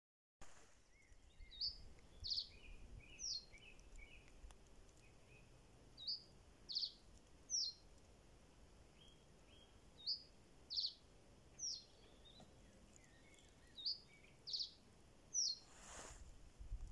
камышевая овсянка, Emberiza schoeniclus
Administratīvā teritorijaRēzeknes novads
СтатусПоёт